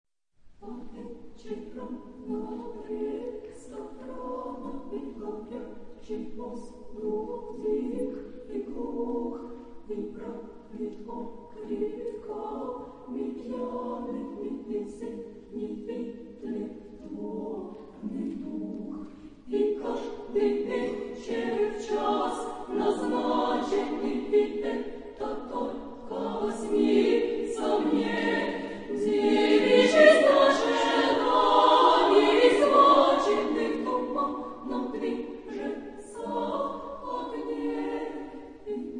Epoque: 20th century  (1950-1999)
Genre-Style-Form: Choral song ; Secular
Type of Choir: SSSAA  (5 women voices )
Tonality: A minor